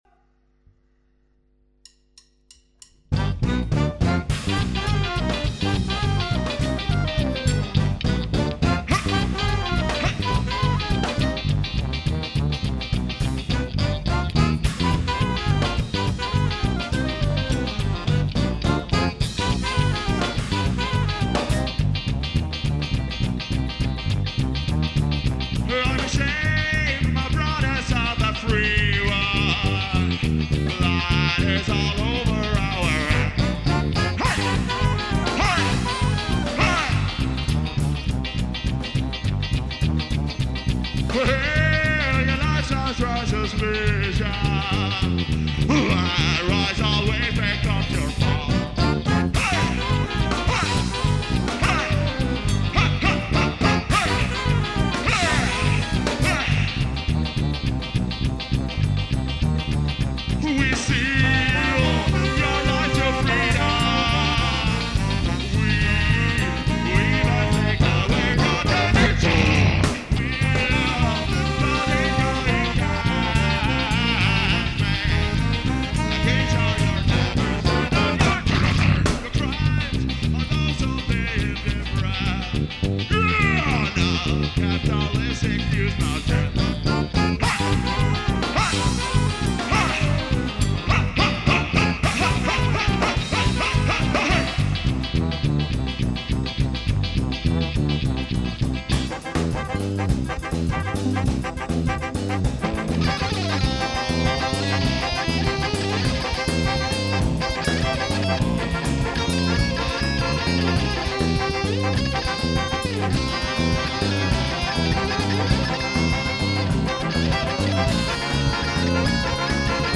Live material